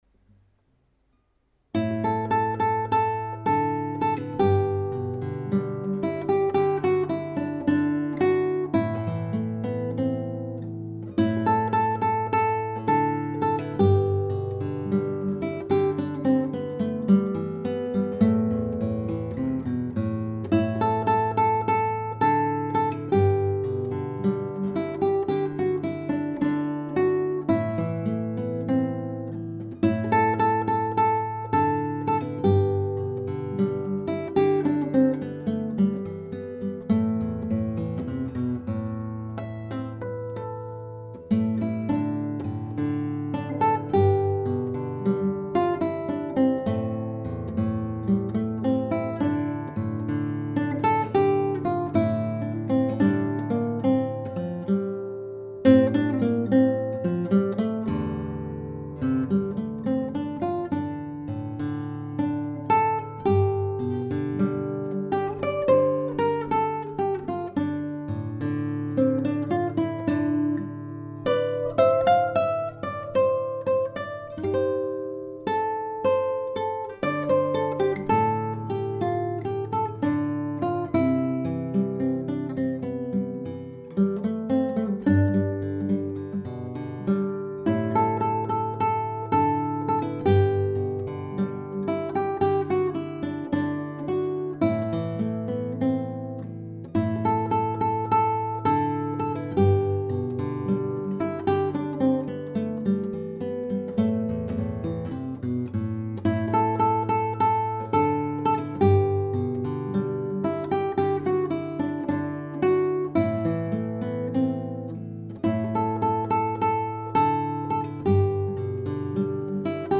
[古典吉他视听] 冬阳
好听，录音效果也挺棒，如果是视频就更好了！
自己关在小房间里，用手机+小麦克风录的。
音色不错，挺有感情！感觉节拍要加强一下。